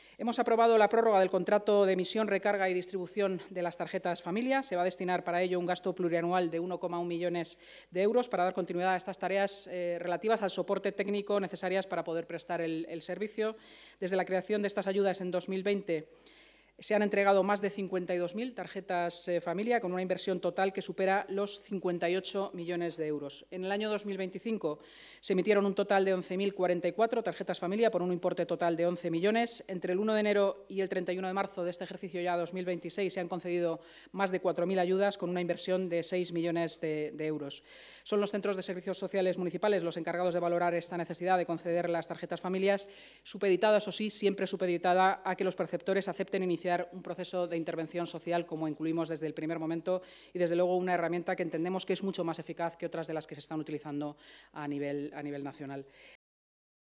La vicealcaldesa y portavoz municipal, Inma Sanz, ha informado de que se destinará un gasto plurianual de 1,1 millones de euros para dar continuidad a las tareas de soporte técnico necesarias para poder prestar este servicio.